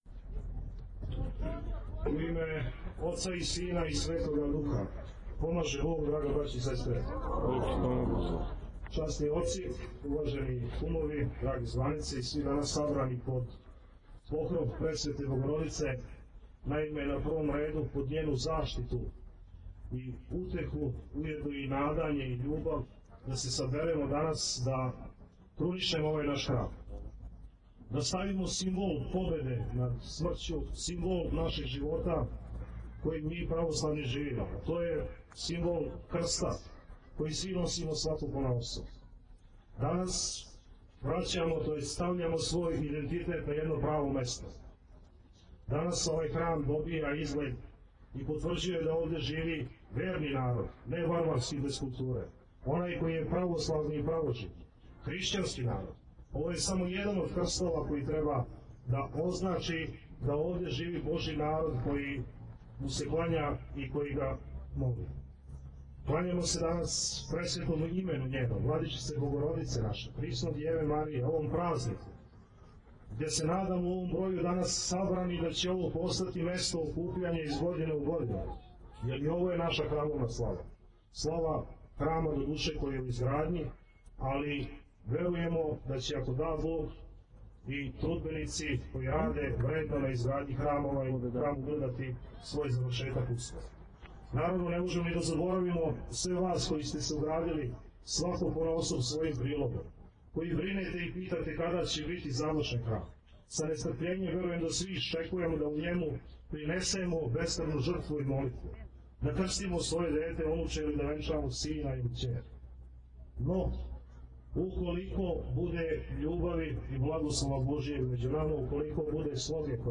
Молитвено славље у бачкопаланачком насељу Синај
• Беседа